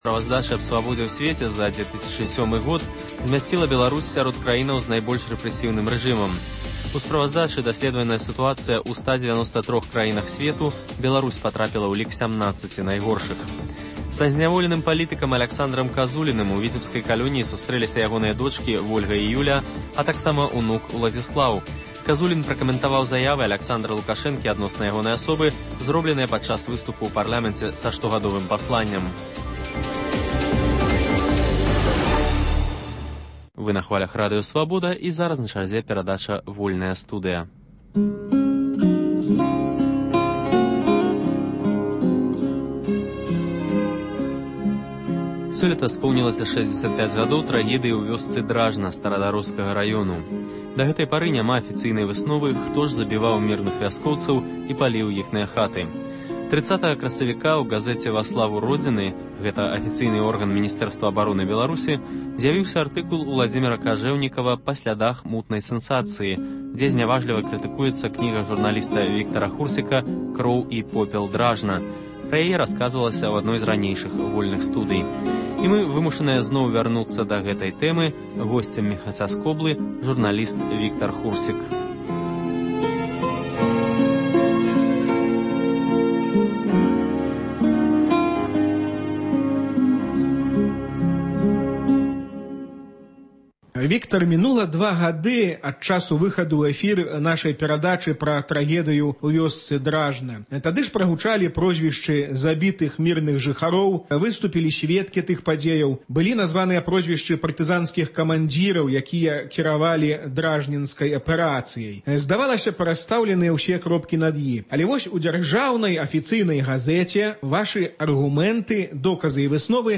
Сёлета споўнілася 65 гадоў трагедыі ў вёсцы Дражна Старадароскага раёну. Гутарка